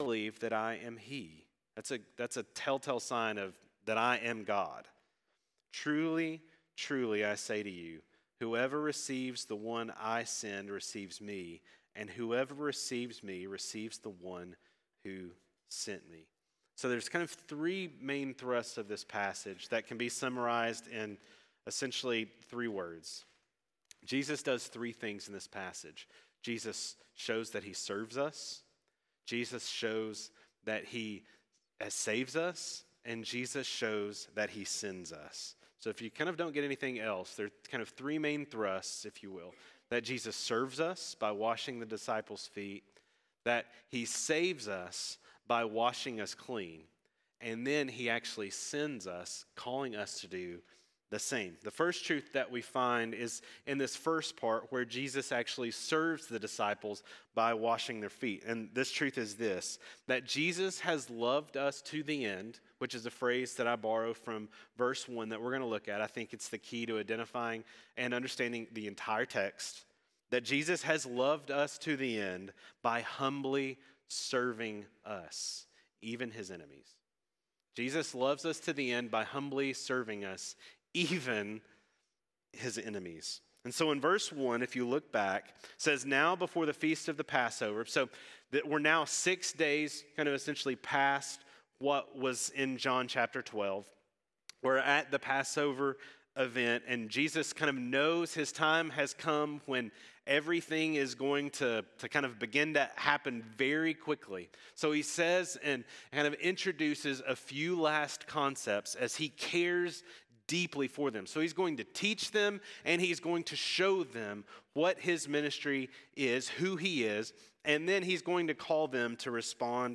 Loved to the End: John 13:1-20 (Recording Started Late) – Ridgedale Baptist Church
Sermons